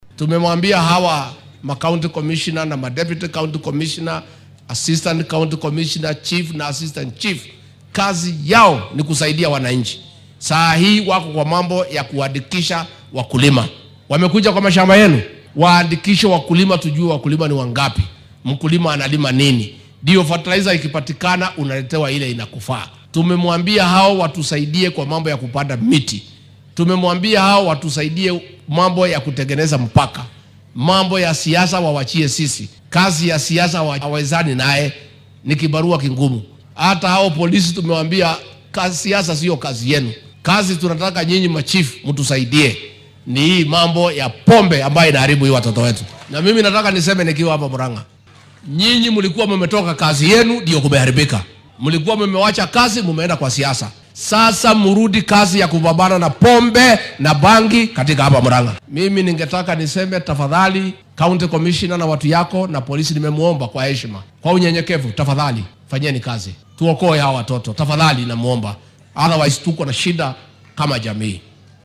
Hadalkan ayuu shalay ka jeediyay ismaamulka Murang’a oo uu uga qayb galay munaasabad lagu soo bandhigayay barnaamijka ardayda looga bixiyo qarashaadka waxbarashada.